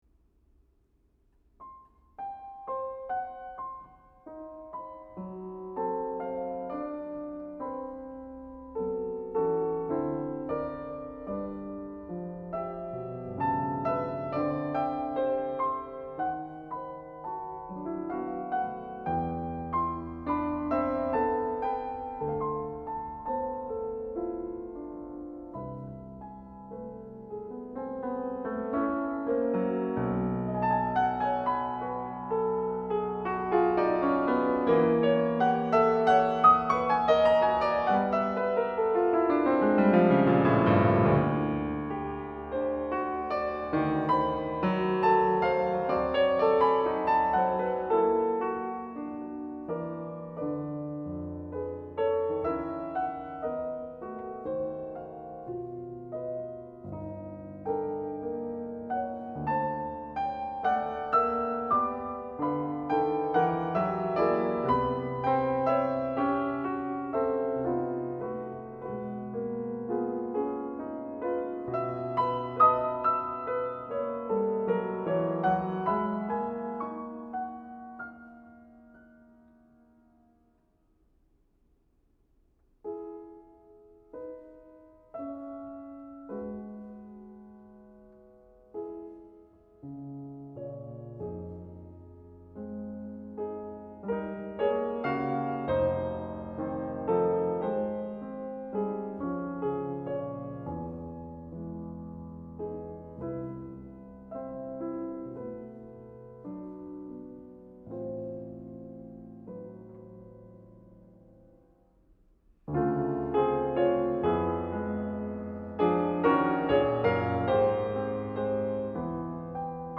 a Piano Fantasy
Pianist